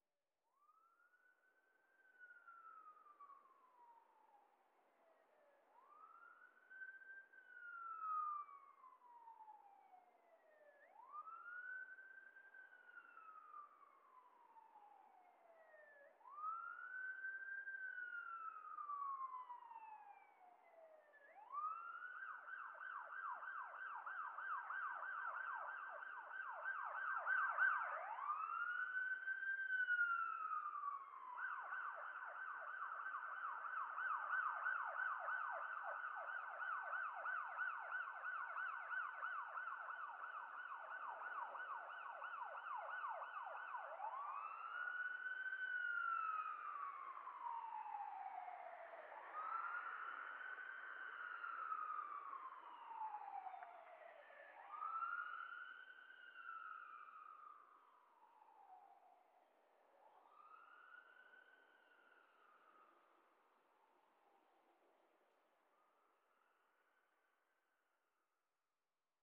Где-то мяучит кошка